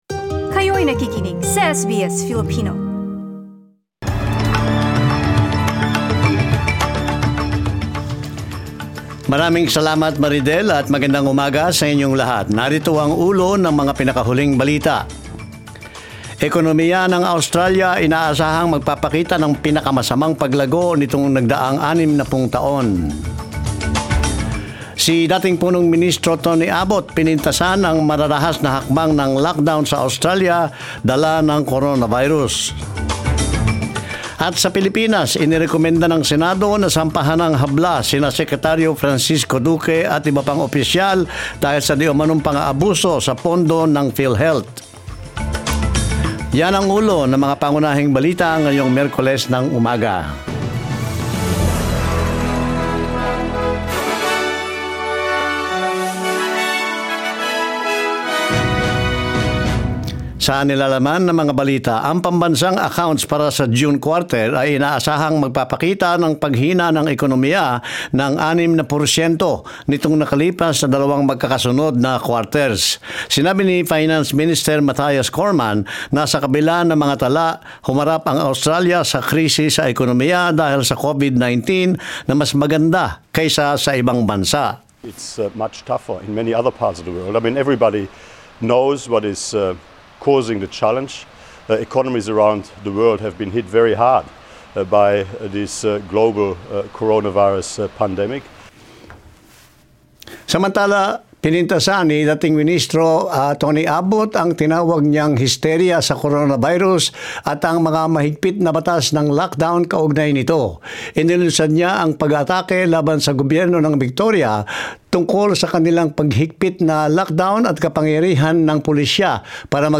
SBS News in Filipino, Wednesday 02 September